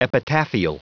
Prononciation du mot epitaphial en anglais (fichier audio)
Prononciation du mot : epitaphial